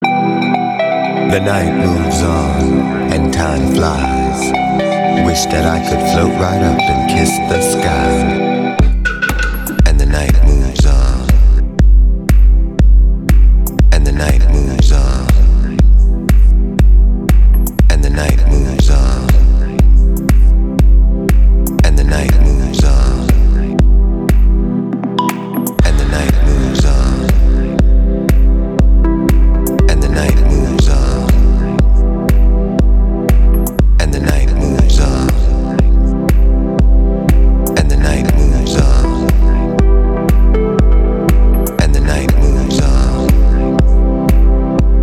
• Качество: 320, Stereo
deep house
Стиль: Deep House